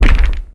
Stone_put.ogg